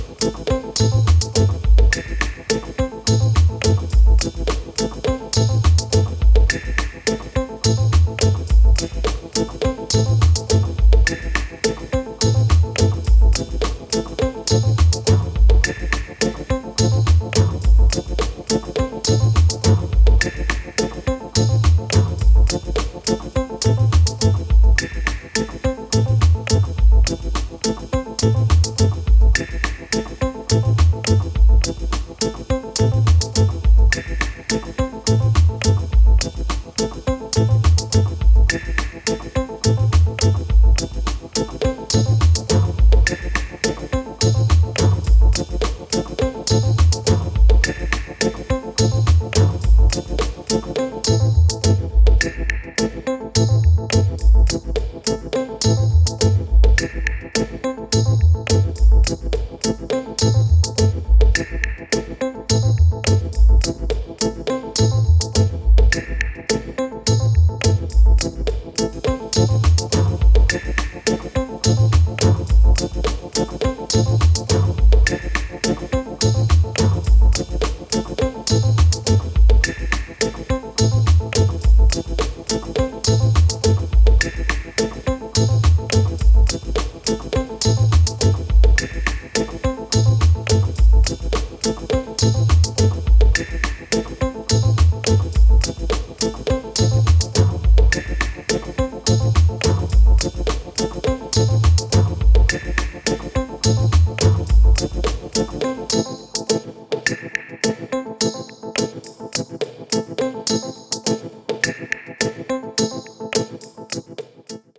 Just a little more relaxed, a little closer to Earth.